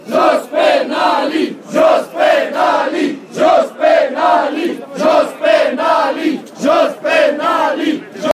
Demonstrații au parcurs marile bulevarde ale Bucureștiului, strigînd numeroase lozinci, anti-corupție:
Romania - Bucharest protests - vox2 „Jos Penalii!”